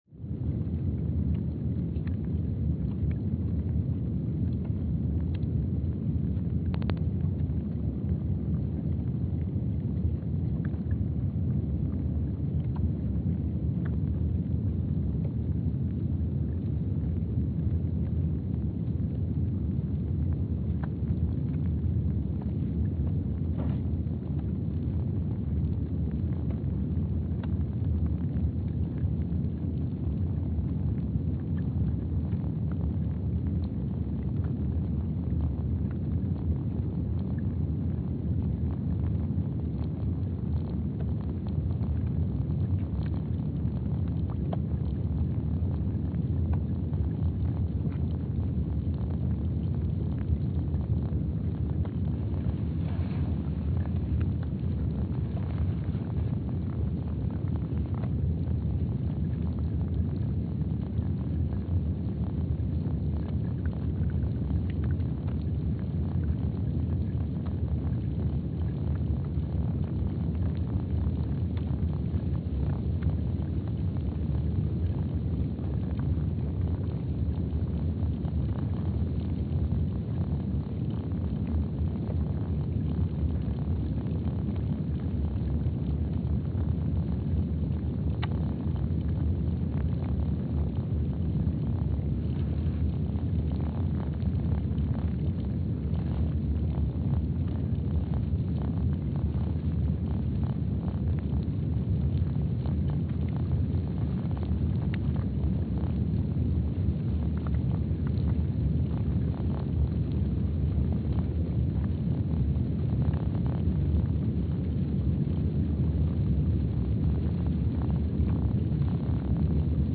Palmer Station, Antarctica (seismic) archived on July 11, 2025
Station : PMSA (network: IRIS/USGS) at Palmer Station, Antarctica
Sensor : STS-1VBB_w/E300
Speedup : ×500 (transposed up about 9 octaves)
Loop duration (audio) : 05:45 (stereo)
Gain correction : 25dB
SoX post-processing : highpass -2 90 highpass -2 90